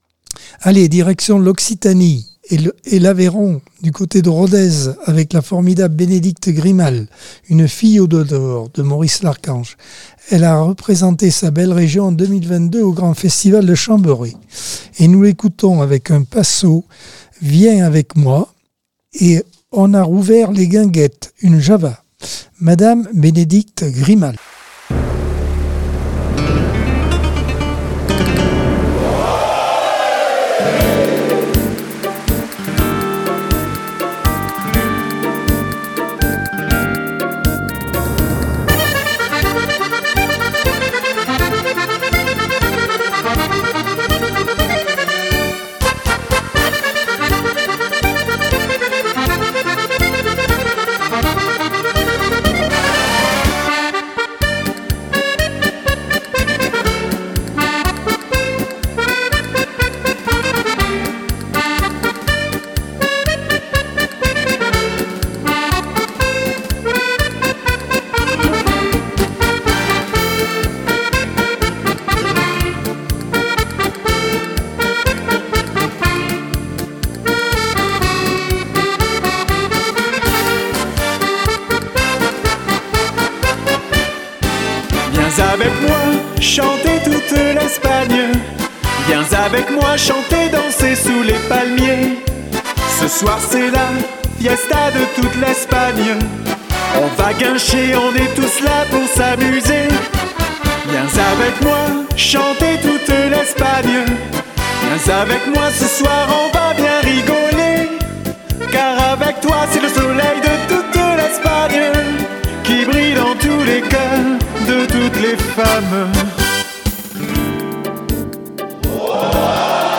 Accordeon 2024 sem 23 bloc 2 - Radio ACX